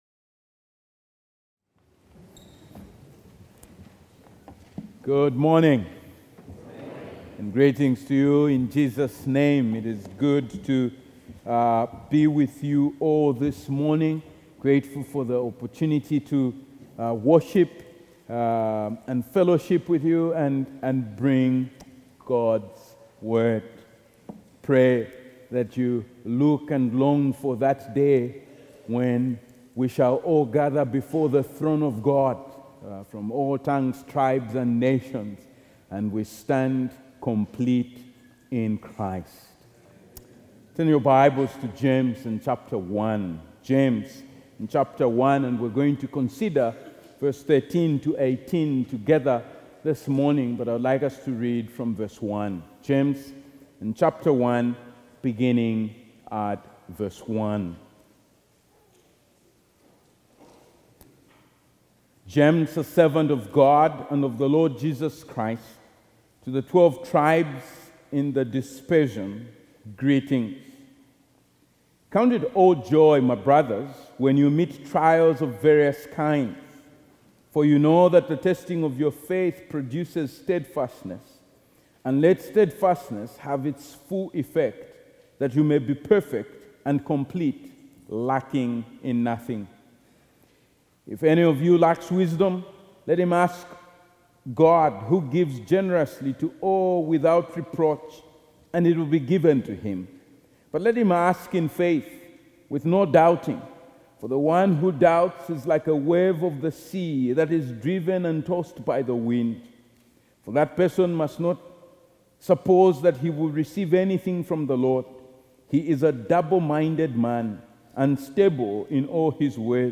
Video & Sermon Audio